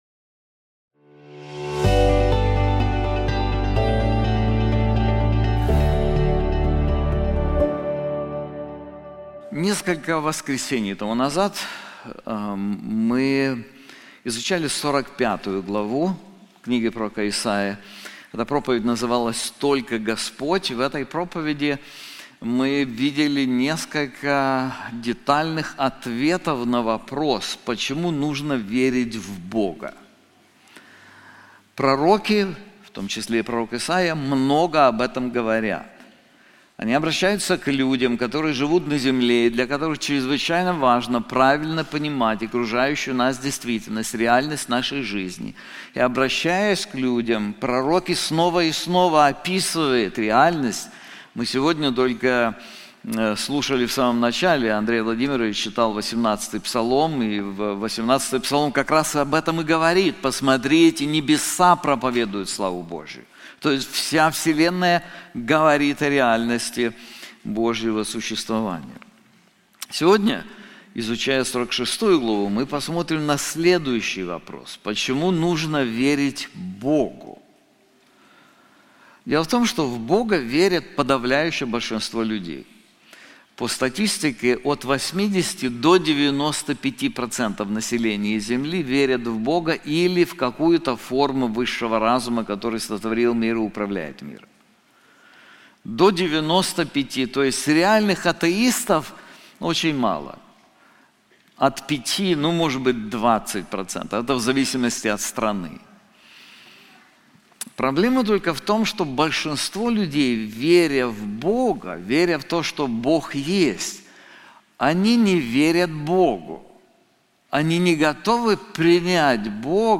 This sermon is also available in English:Why We Must Trust the Lord • Isaiah 46:1-13